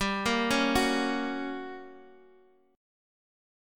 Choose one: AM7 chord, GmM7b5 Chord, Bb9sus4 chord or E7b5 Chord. GmM7b5 Chord